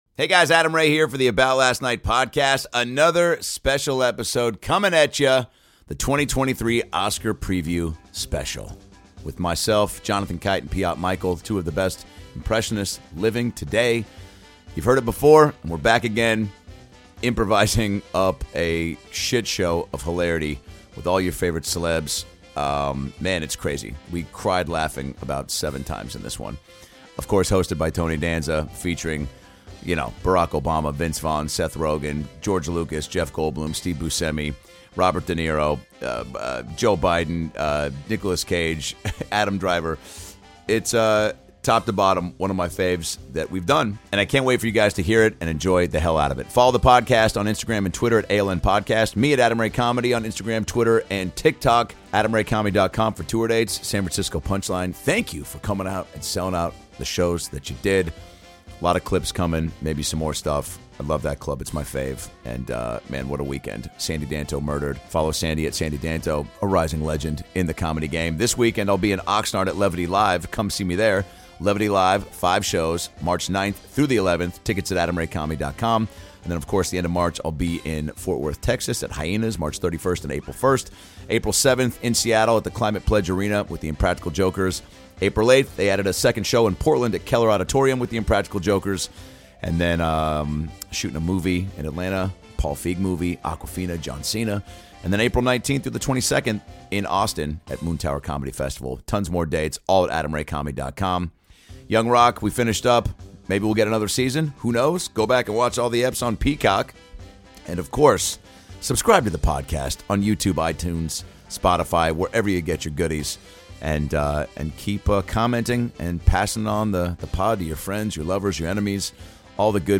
This episode also features appearances from Presidents Joe Biden, Donald Trump and Barak Obama.